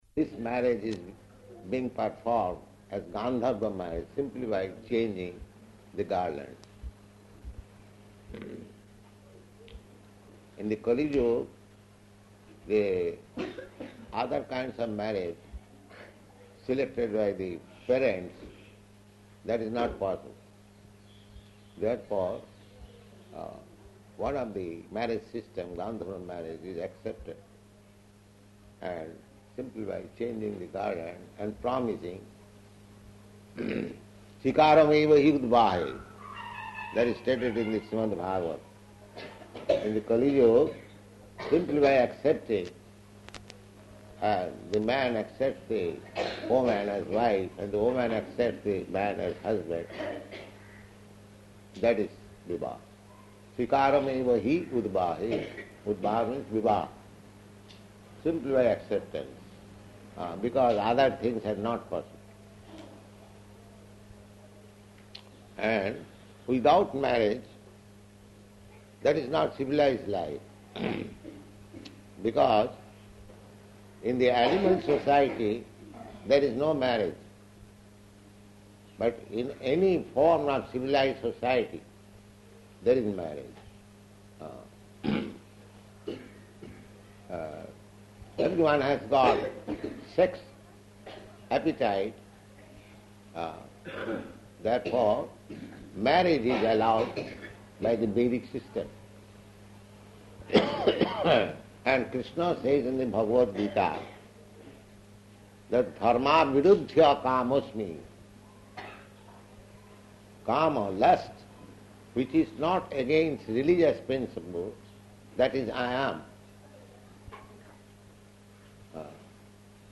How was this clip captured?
Wedding Lecture [partially recorded] Location: Delhi